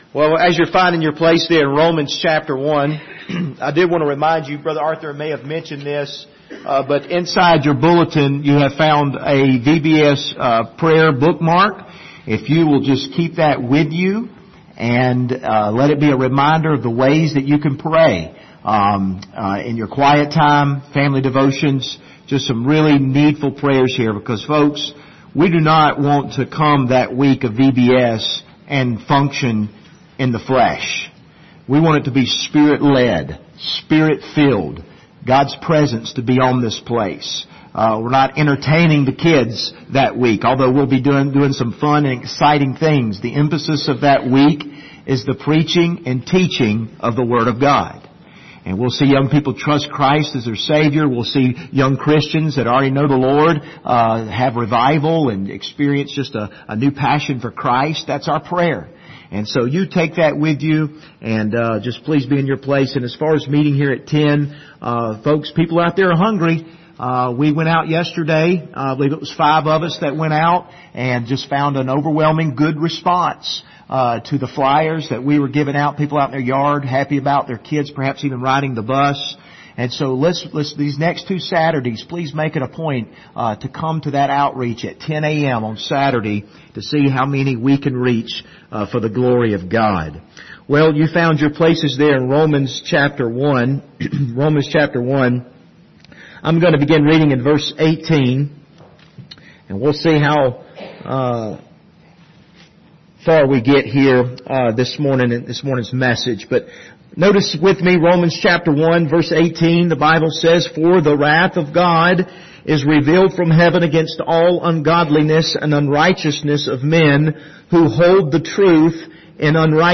The Just Shall Live By Faith Passage: Romans 1:18-32 Service Type: Sunday Morning « Truth